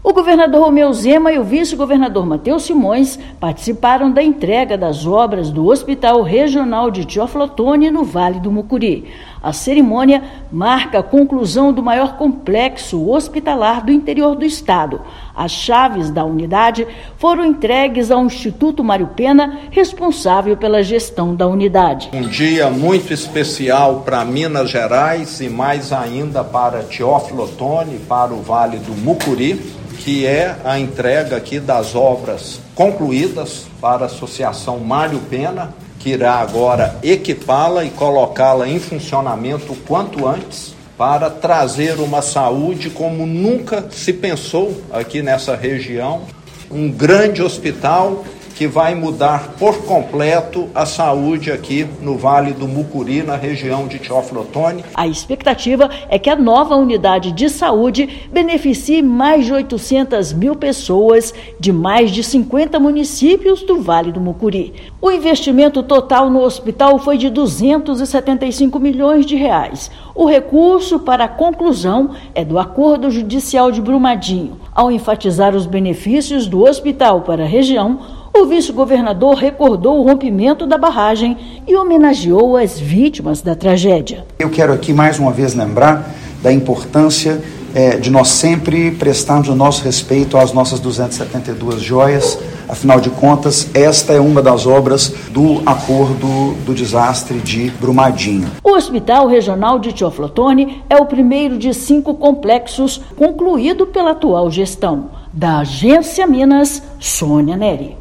Com mais de 22 mil metros quadrados de área construída, hospital abrigará 427 leitos, incluindo internações adulto, pediátrica e maternidade. Ouça matéria de rádio.